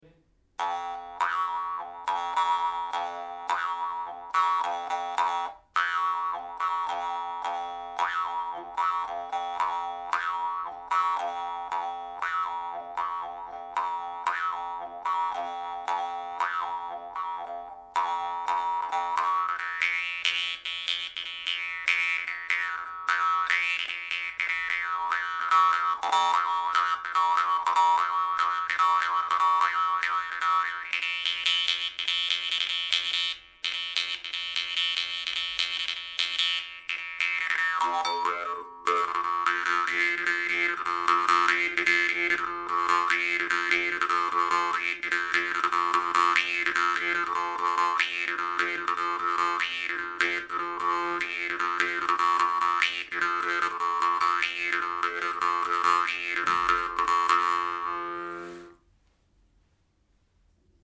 Комментарий: Якутский хомус безупречной обработки, очень сильный звук.
Это в свою очередь позволяет выставлять очень узкий зазор между язычком хомуса и его деками, добиваясь при этом очень сочных обертонов по всему спектру звучания.
Домашняя студия.